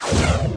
missile.ogg